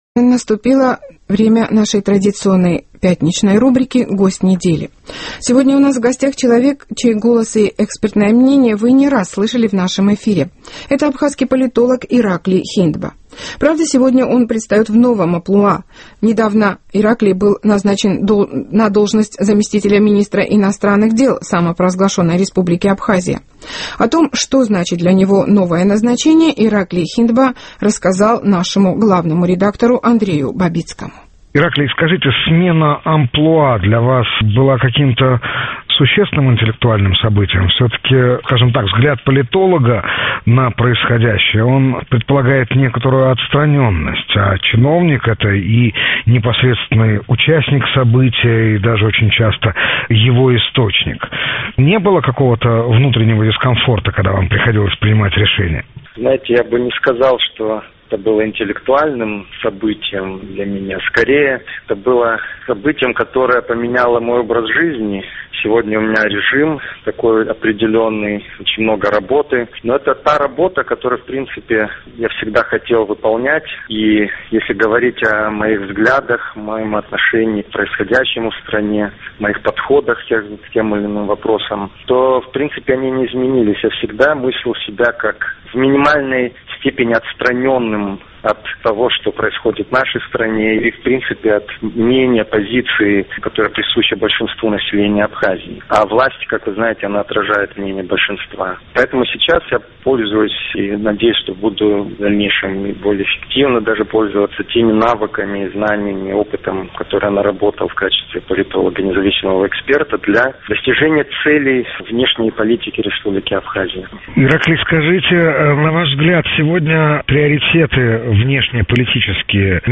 ПРАГА---А сейчас наша традиционная пятничная рубрика «Гость недели».